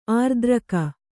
♪ ārdraka